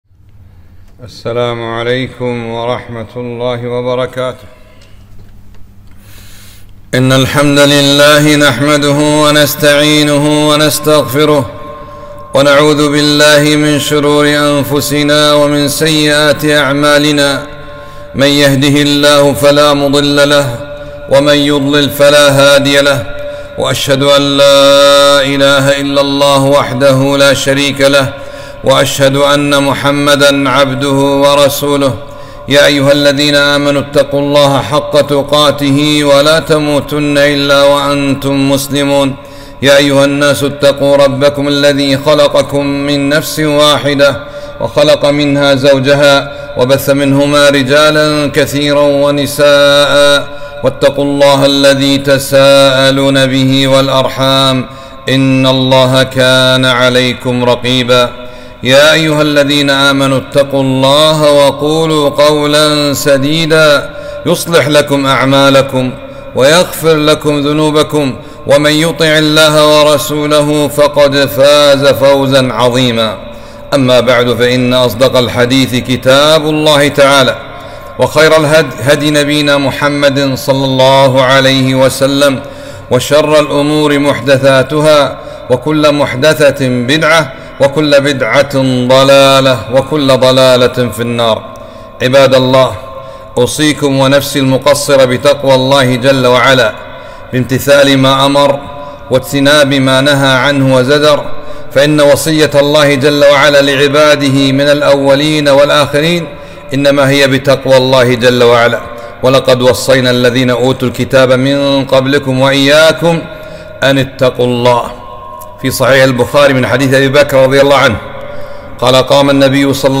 خطبة - (حرمة المسلم عند الله تعالىٰ )